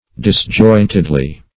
disjointedly - definition of disjointedly - synonyms, pronunciation, spelling from Free Dictionary
Dis*joint"ed*ly, adv.